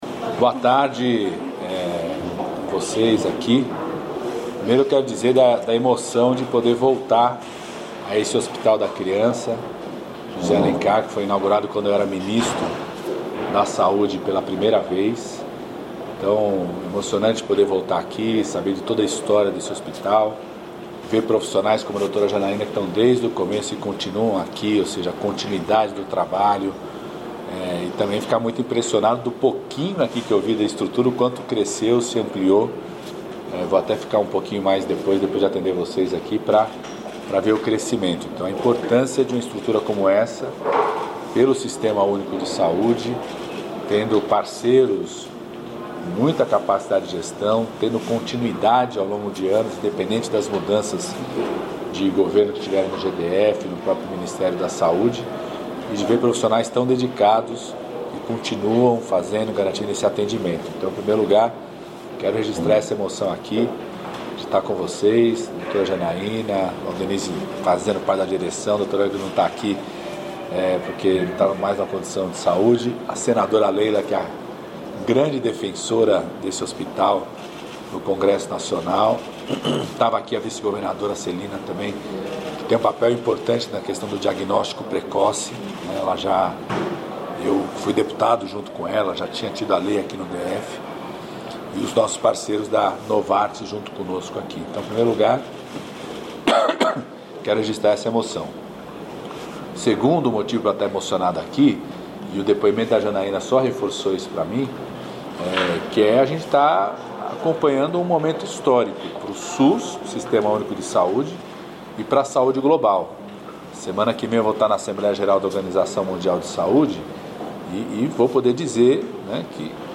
Número representa mais de 98% do total de solicitações feitas até as 16h desta quarta-feira (14), primeiro dia de requerimentos pelo sistema Meu INSS. Ouça a coletiva completa com os presidentes do INSS, Gilberto Waller Jr, e da Dataprev, Rodrigo Assunção.